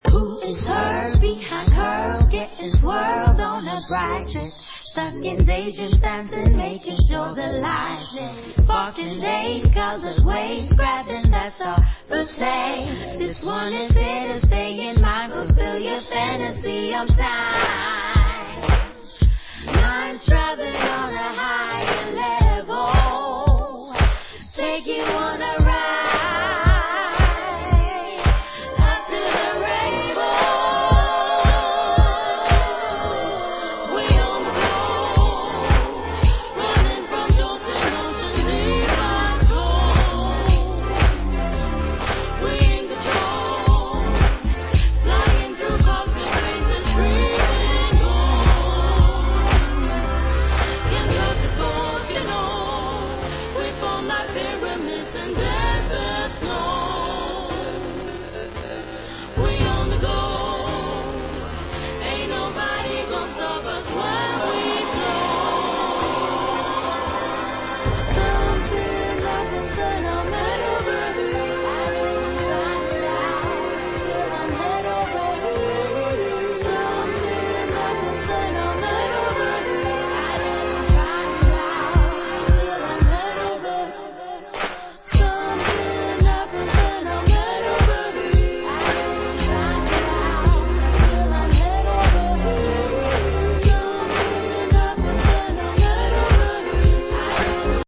Single sided dub version excursion